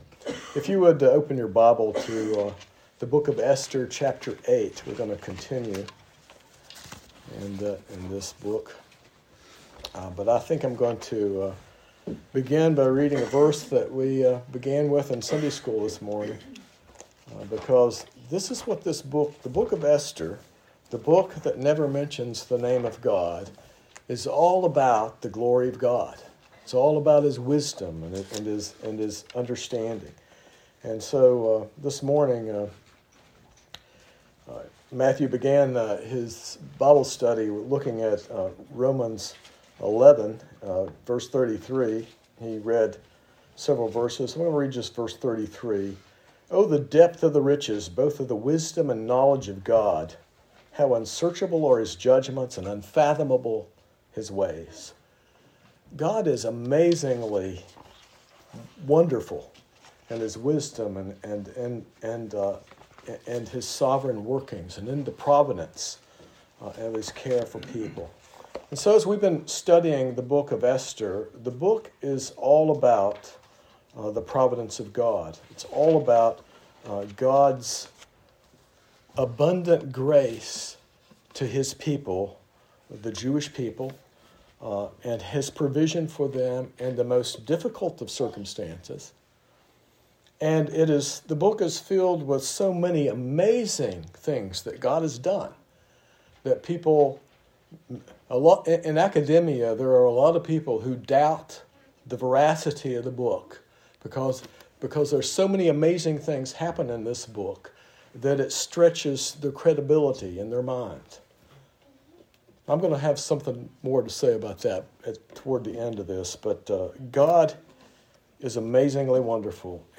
This sermon explores God’s amazing providence, wisdom, and grace as revealed in the book of Esther, highlighting how God works through seemingly impossible circumstances to protect His people and fulfill His promises. It also connects these themes to the ongoing work of ministries like the Gideons.